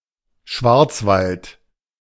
Die Swartwoud (Duits: Schwarzwald, [ˈʃvaʁt͡svalt],